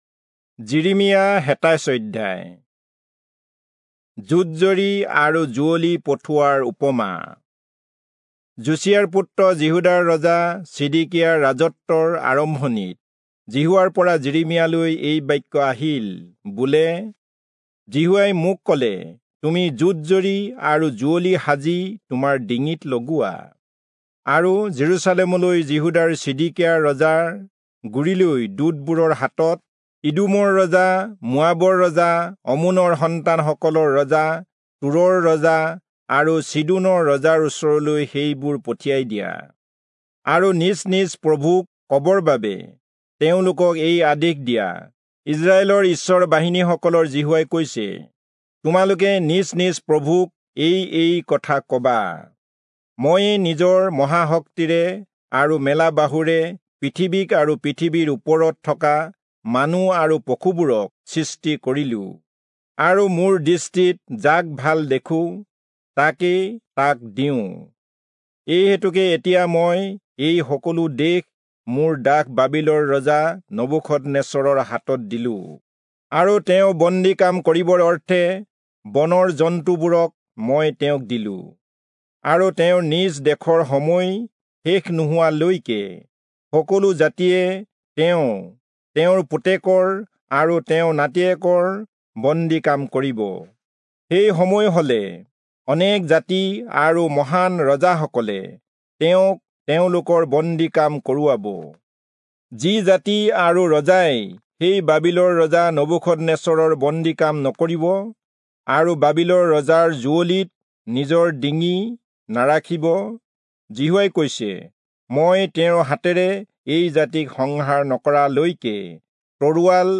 Assamese Audio Bible - Jeremiah 1 in Urv bible version